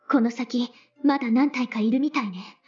Subject description: if you want to sound a yuri clone as anime girl.
Enjoy some Weeb Anime Voices.